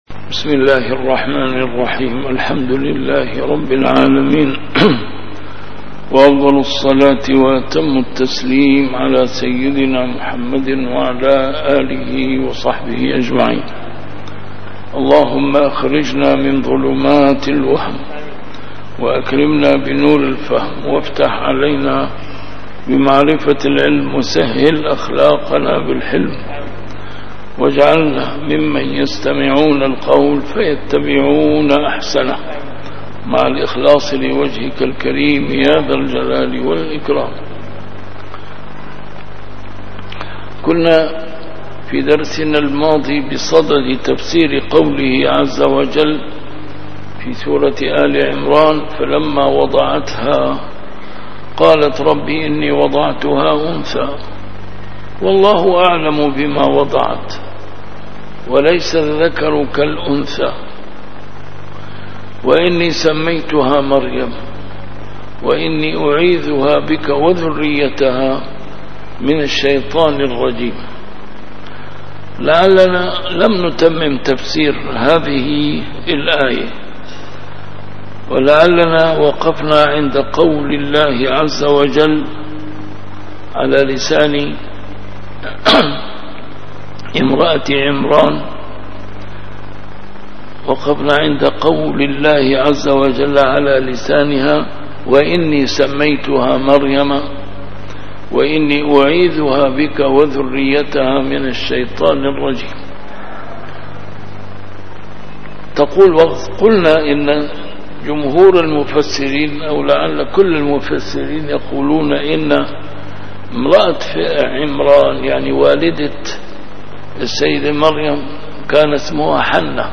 A MARTYR SCHOLAR: IMAM MUHAMMAD SAEED RAMADAN AL-BOUTI - الدروس العلمية - تفسير القرآن الكريم - تفسير القرآن الكريم / الدرس السبعون بعد المائة: سورة آل عمران: الآية 36-37